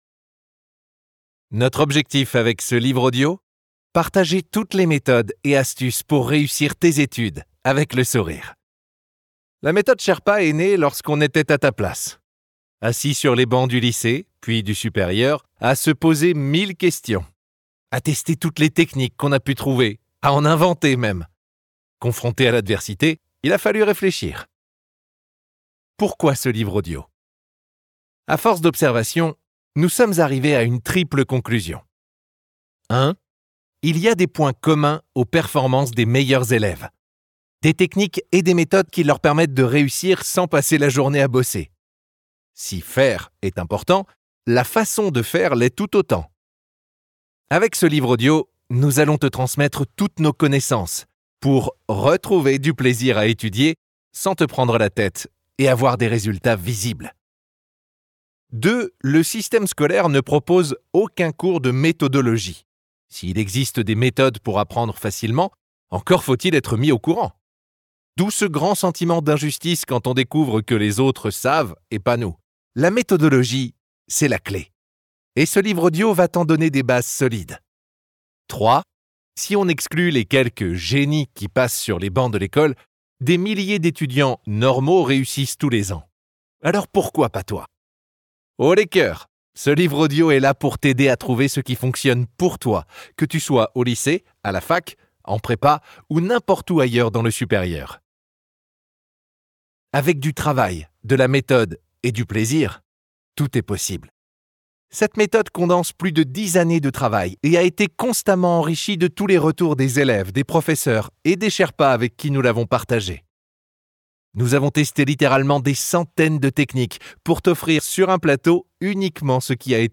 Ce livre audio te présente toutes les techniques et astuces pour apprendre plus efficacement, plus vite, sans y passer tes nuits... et réussir tes examens !